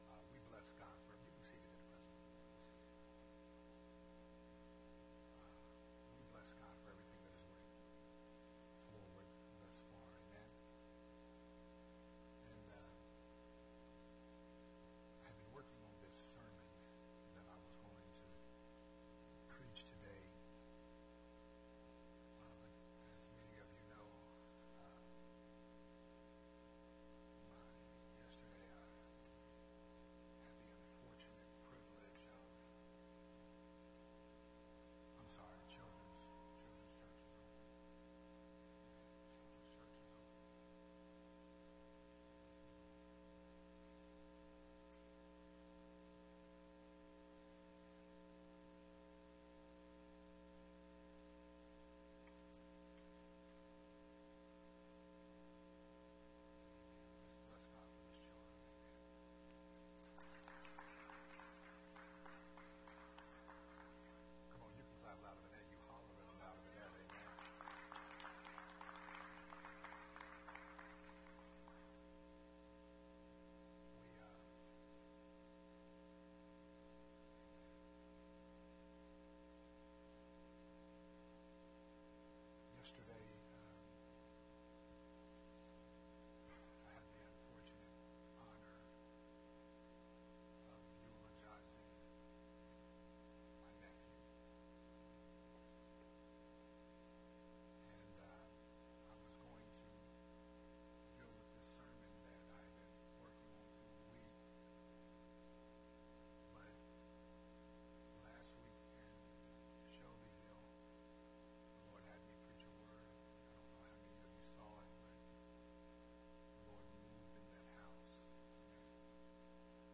This sermon was recorded at Unity Worship Center on March 13th, 2022.